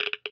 geiger_8.ogg